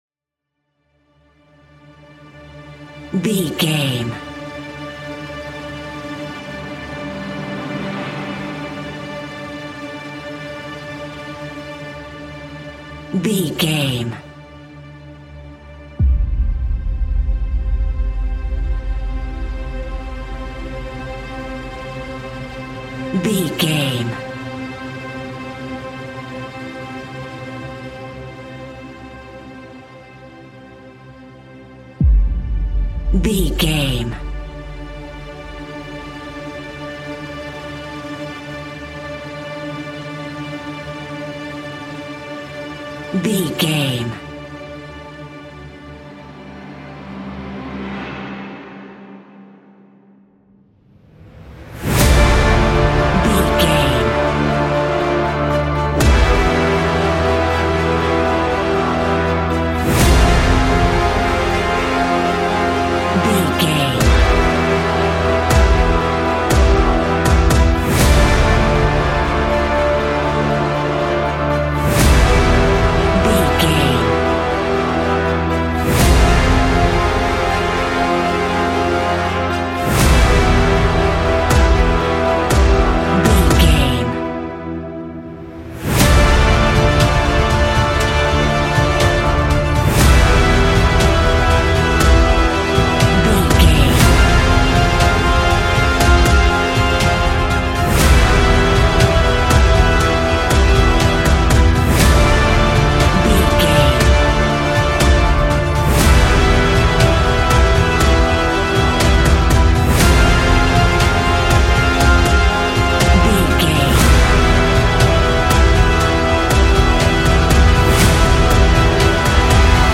Ionian/Major
epic
hopeful
magical
energetic
pompous
cello
horns
violin
percussion
orchestra
piano
trumpet